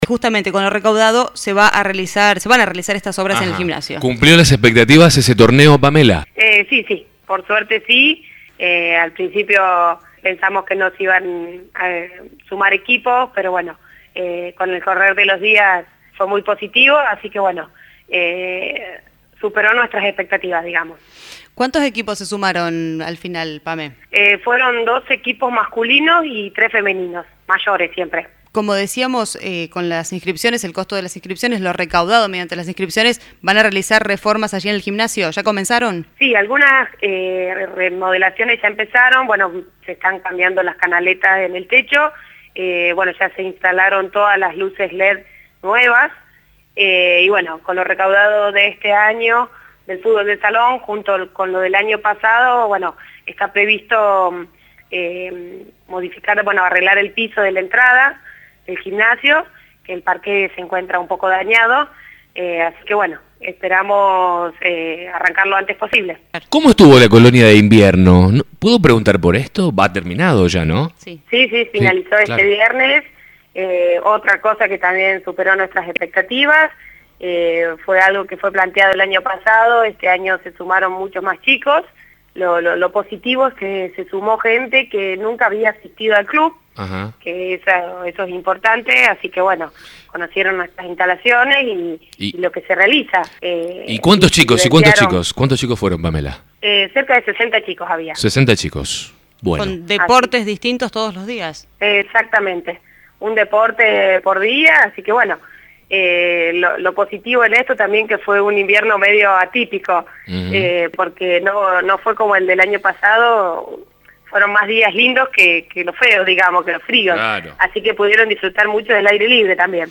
Escuchá un extracto del diálogo: